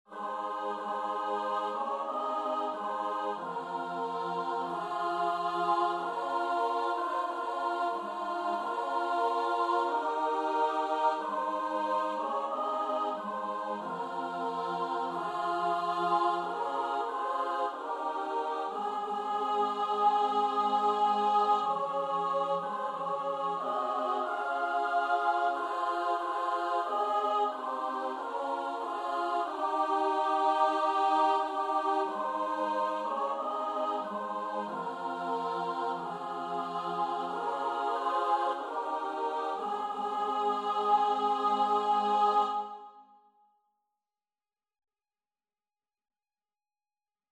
Free Sheet music for Choir (SSAA)
Ab major (Sounding Pitch) (View more Ab major Music for Choir )
Andante = c. 92
4/4 (View more 4/4 Music)
Choir  (View more Intermediate Choir Music)
Traditional (View more Traditional Choir Music)
bleak_midwinter_SSAA.mp3